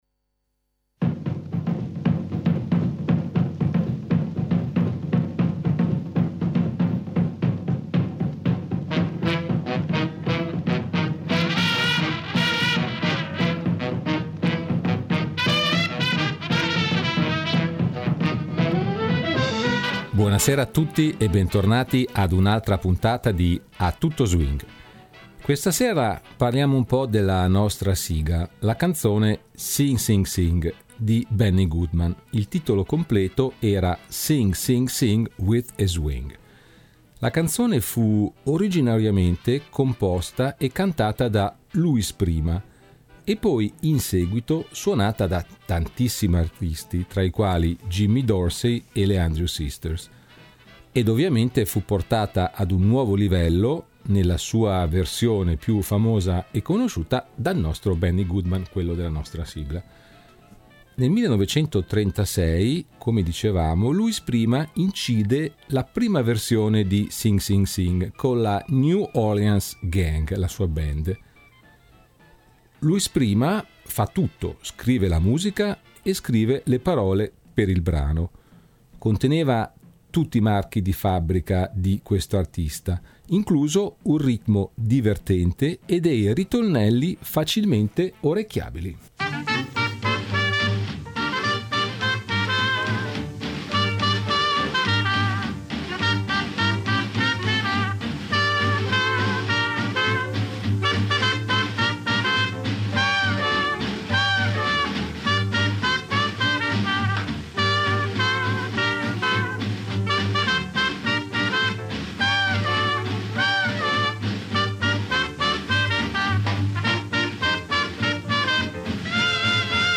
E poi, sempre in questa puntata, vediamo un po’ come si svolgevano le registrazioni dei brani su disco. Ovviamente sempre con tanta musica !